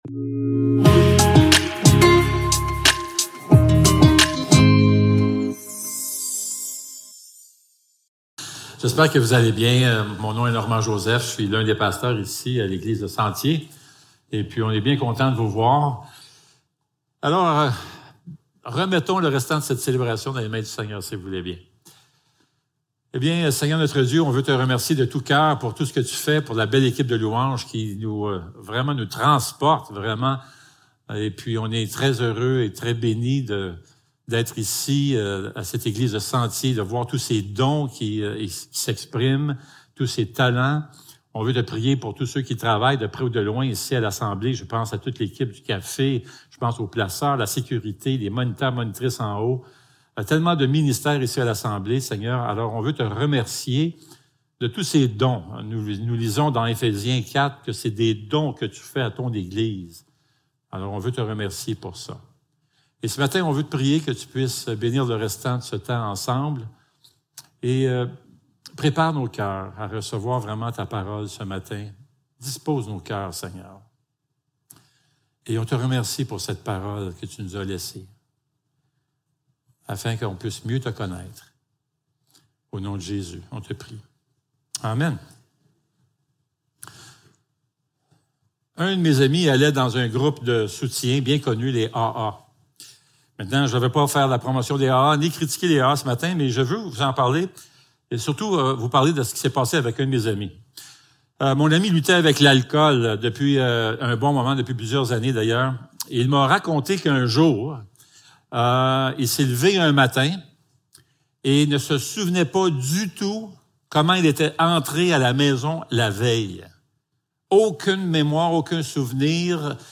1 Jean 4.1-6 Service Type: Célébration dimanche matin Description